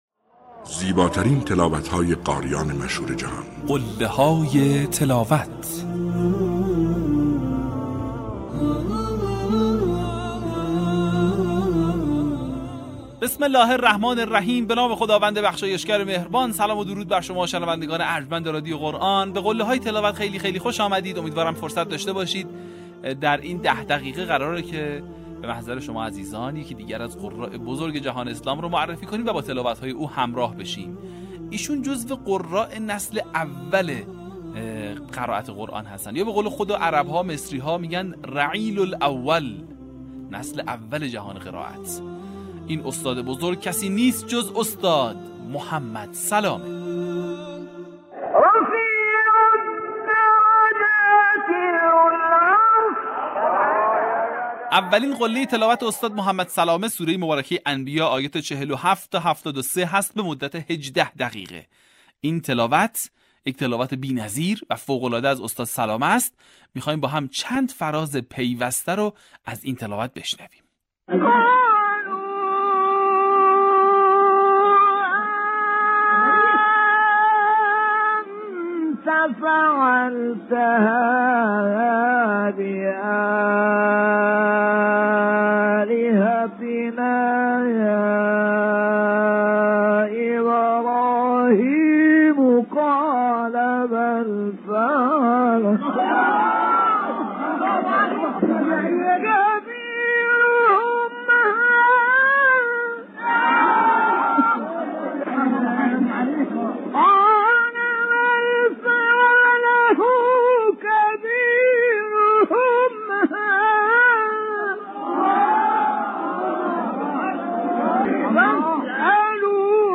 در قسمت چهل‌وهفتم فراز‌های شنیدنی از تلاوت‌های به‌یاد ماندنی استاد محمد سلامه را می‌شنوید.